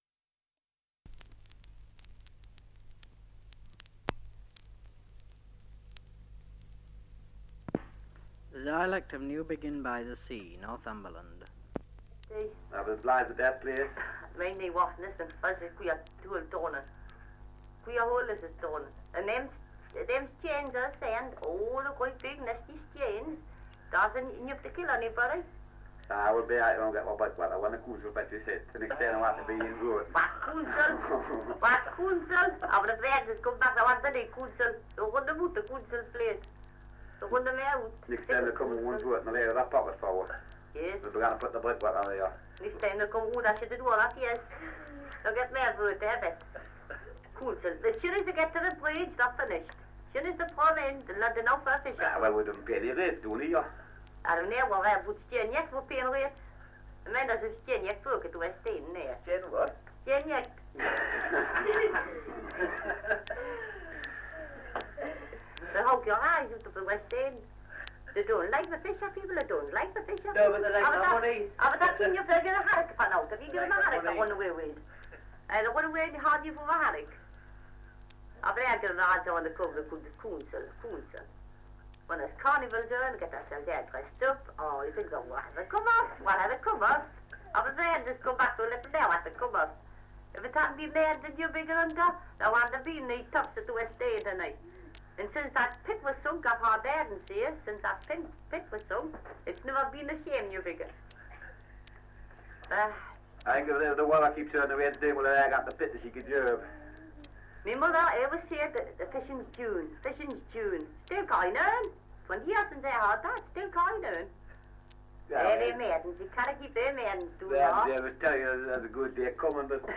Dialect recording in Newbiggin-by-the-Sea, Northumberland. Dialect recording in Wooler, Northumberland
78 r.p.m., cellulose nitrate on aluminium